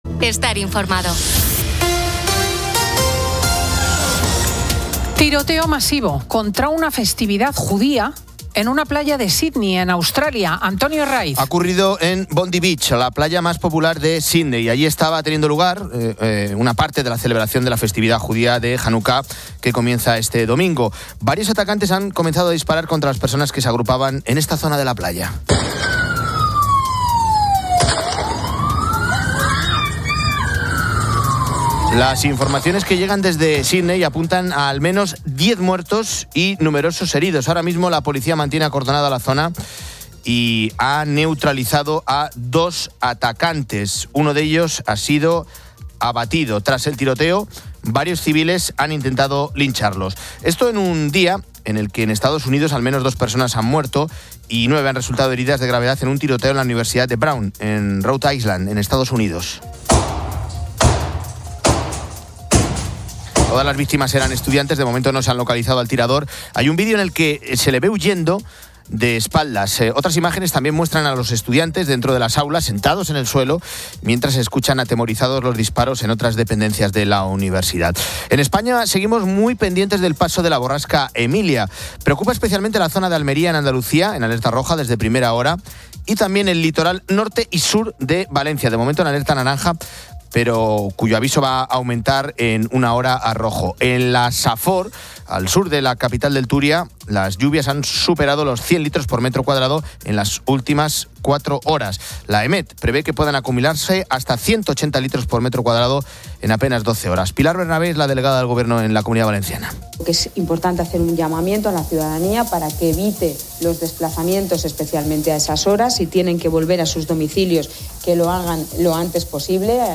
Hora completa del programa Fin de Semana de 10:00 a 11:59